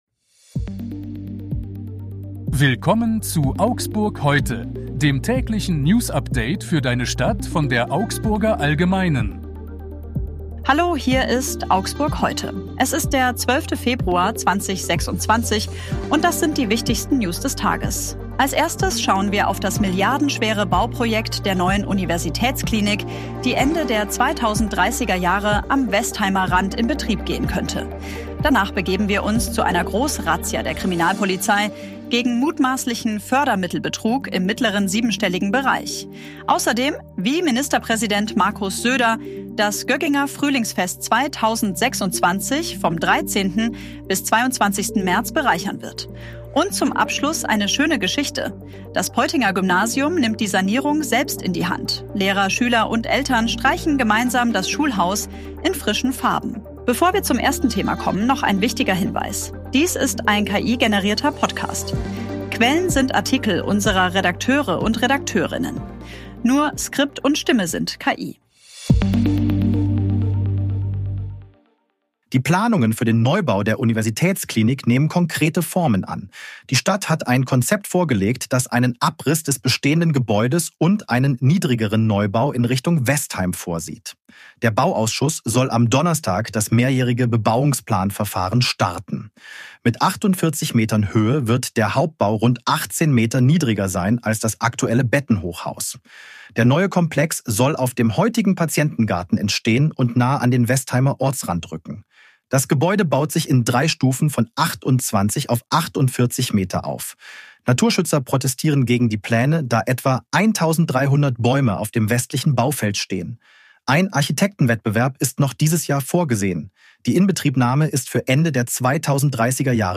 Nur Skript und Stimme sind KI.